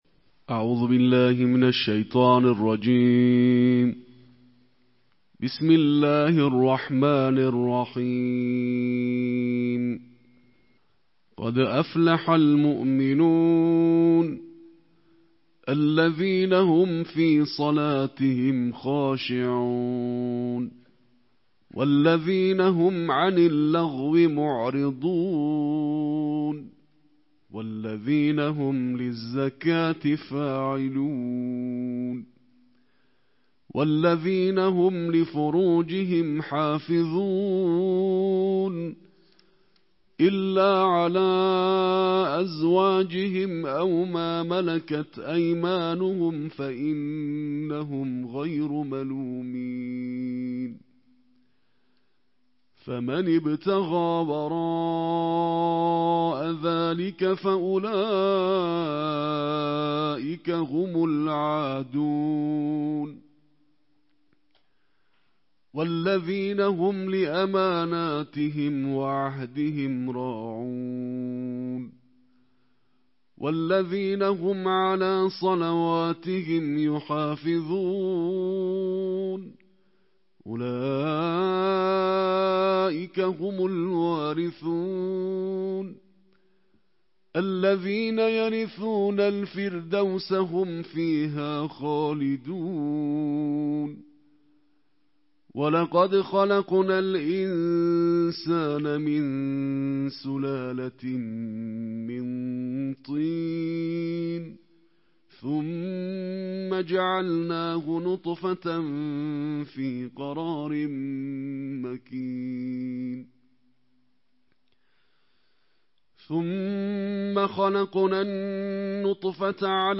Beynəlxalq qarilərin səsi ilə Quranın on səkkzinci cüzünün qiraəti
Quranın on səkkzinci cüzünün qiraəti